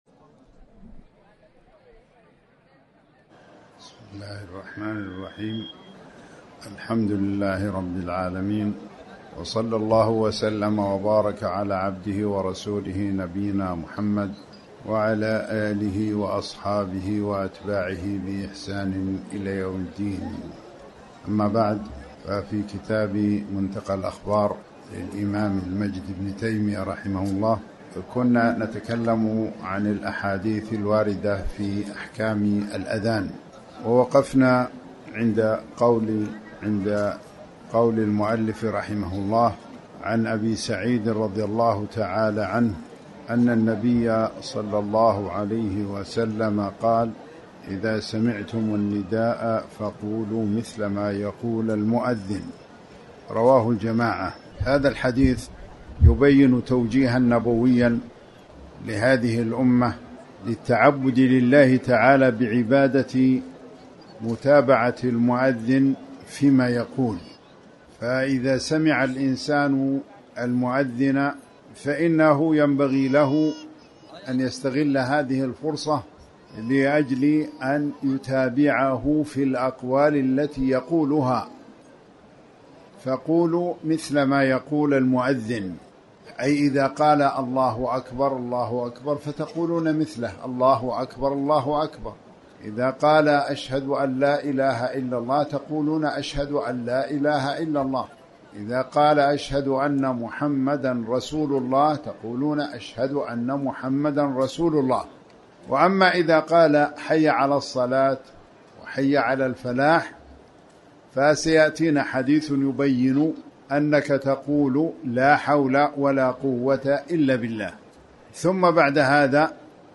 تاريخ النشر ٢٨ صفر ١٤٤٠ هـ المكان: المسجد الحرام الشيخ